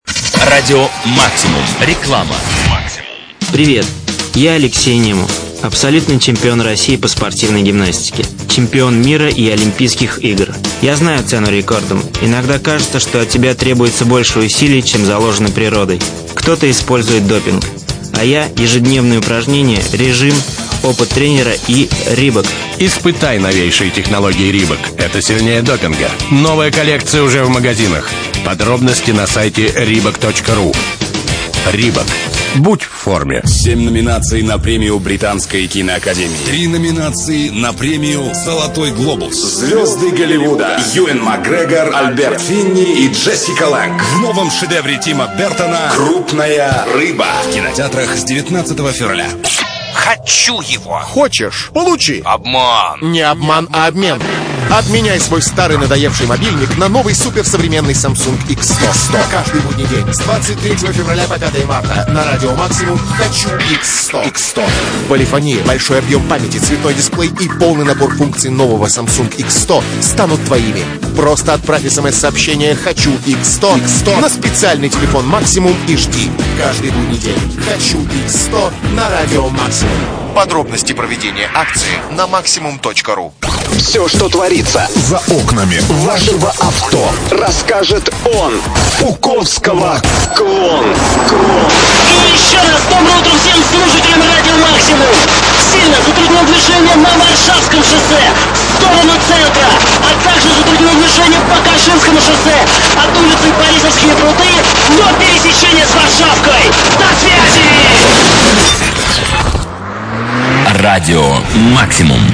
Рекламный блок (Радио Максимум, 24.02.2004) Reebok, "Крупная рыба", Samsung